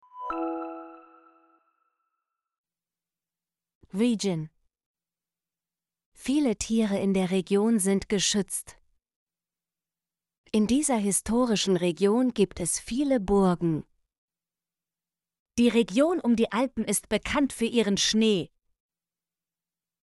region - Example Sentences & Pronunciation, German Frequency List